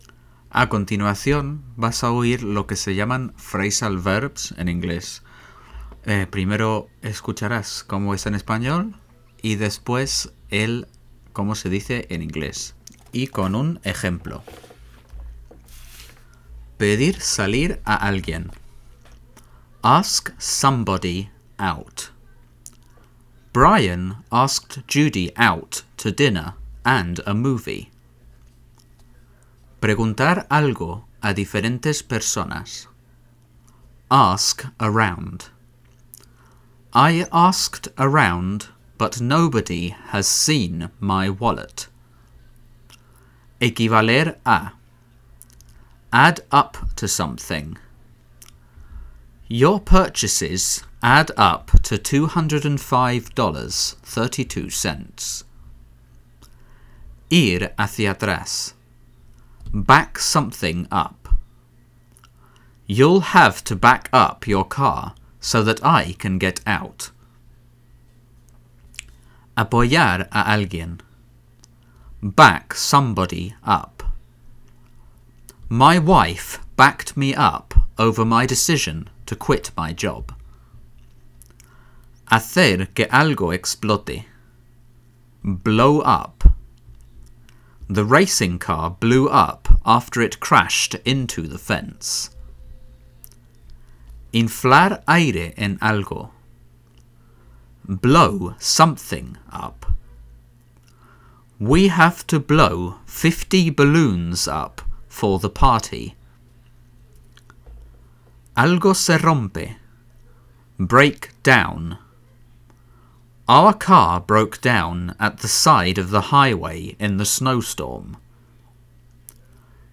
Para ayudarte con tu gramática, he grabado los verbos y phrasal verbs más usados en inglés para aquel que le interese.
Phrasal verbs - Grabados - (Alta Calidad)
Phrasal verbs - HQ.mp3